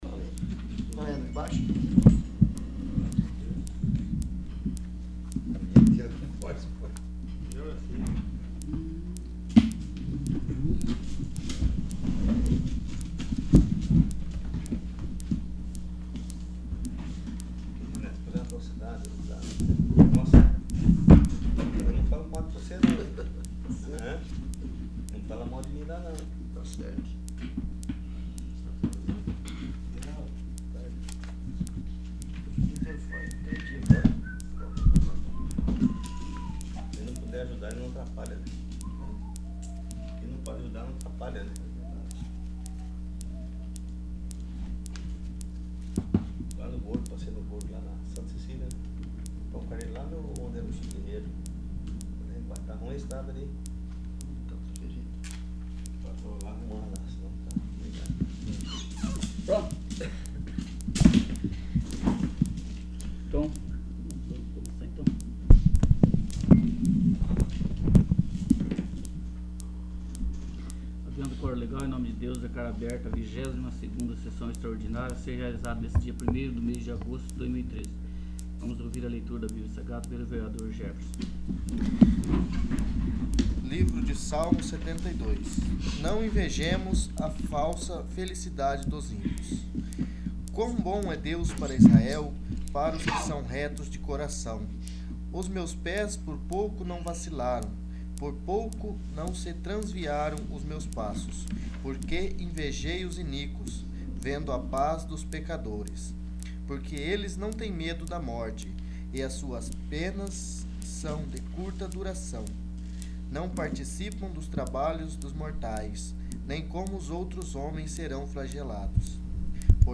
22º. Sessão Extraordinária